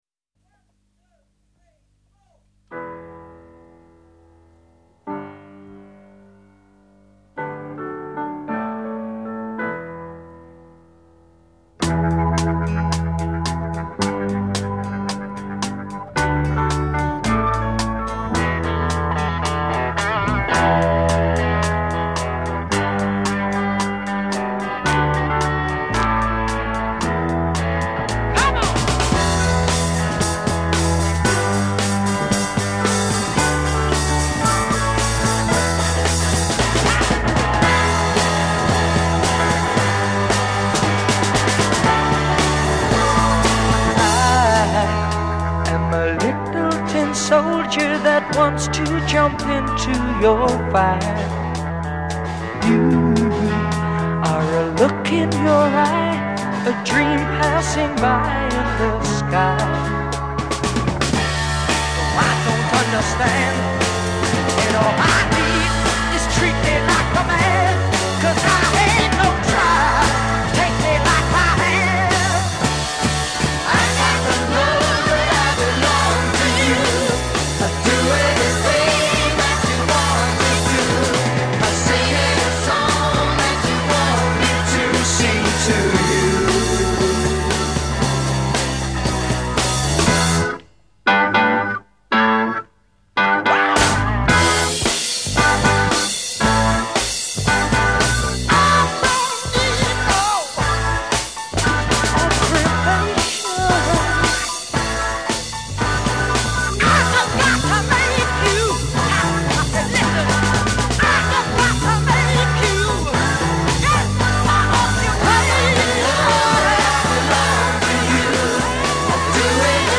Recorded at Olympic Studios.
intro part 1 0:00 4 Piano and electric piano.
part 2 0: 4 Add organ, drum taps, acoustic guitar.
part 5 : 4 Organ sustains.
refrain   : 8 Soloist and chorus in unison. c